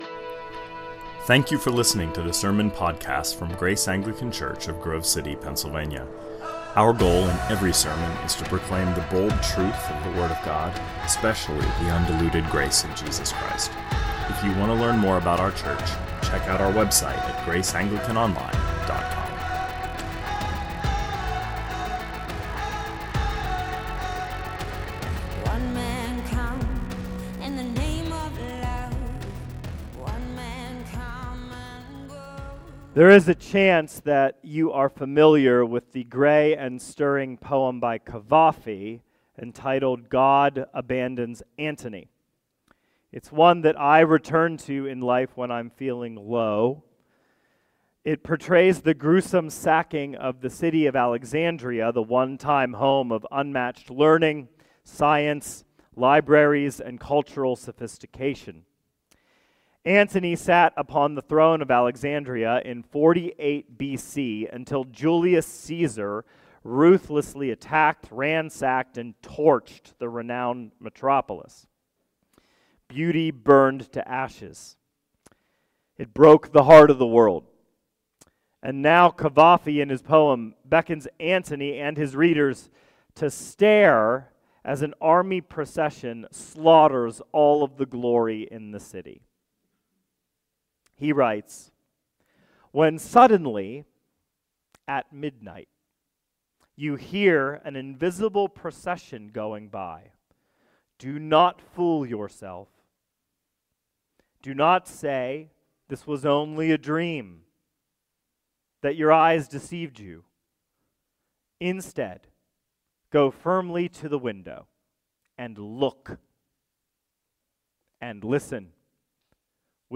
2024 Sermons